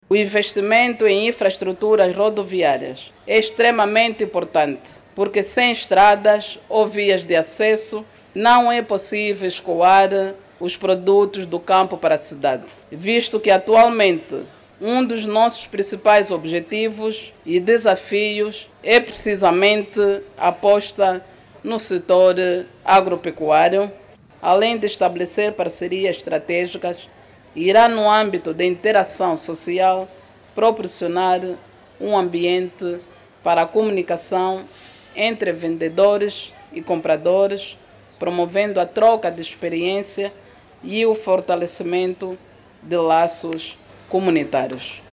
Para o efeito, a governadora provincial, Filomena Miza, espera por mais investimentos nas estradas e de capital para garantir grande produção agrícola e com qualidade. Filomena Miza falava nesta quarta-feira na abertura da Expo-Dundo 2025.